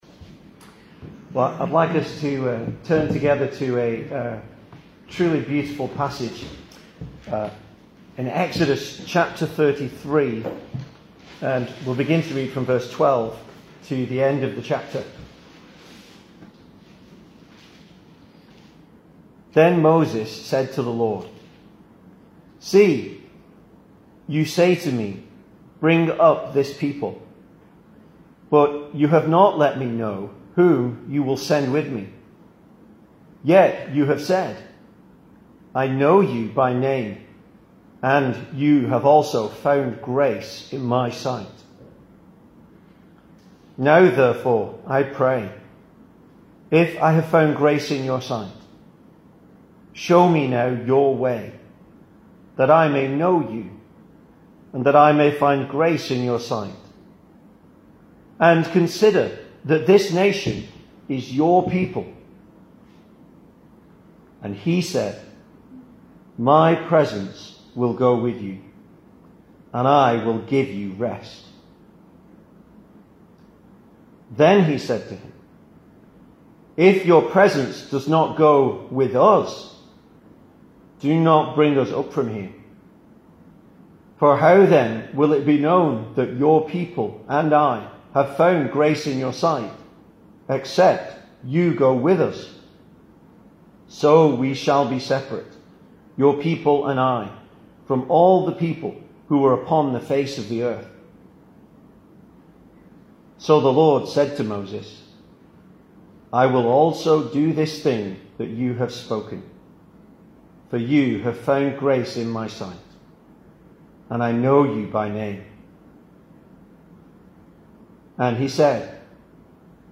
Service Type: Weekday Evening